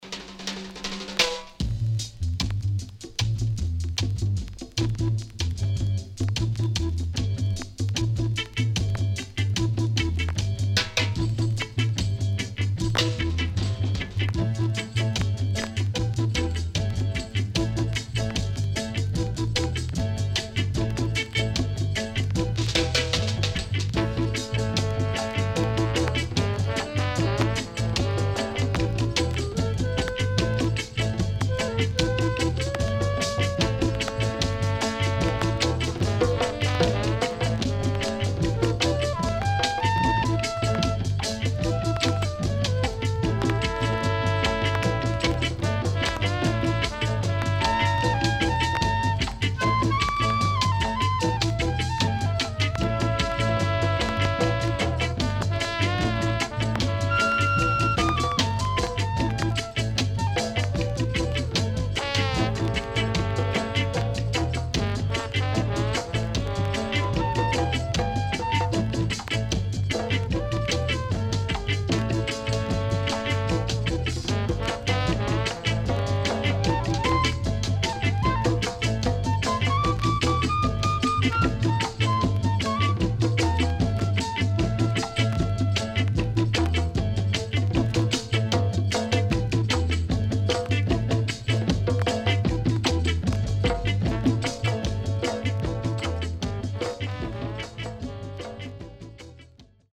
EARLY REGGAE
SIDE A:少しチリノイズ入ります。